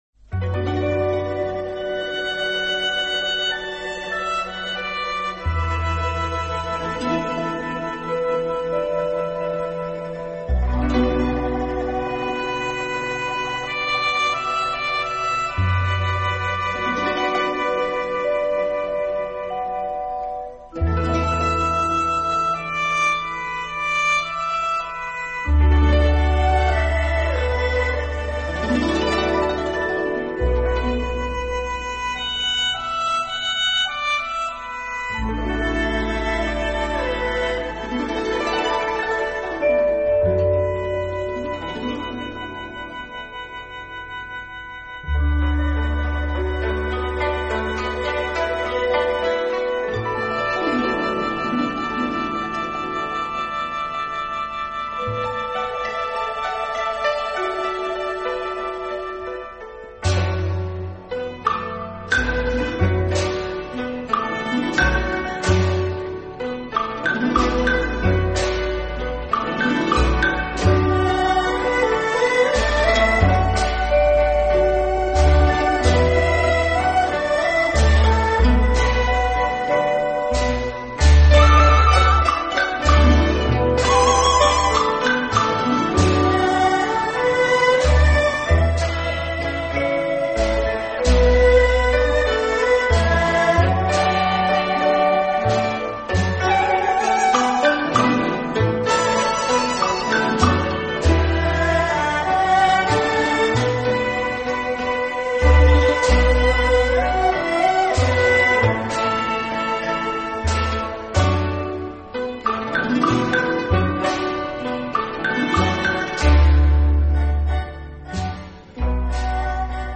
这是一张模拟录音,现场感非常强的由众多国手操弦弄器的场面浩 大的录音作品.